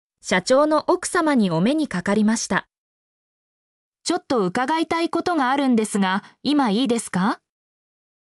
mp3-output-ttsfreedotcom-13_Tyb8JfxA.mp3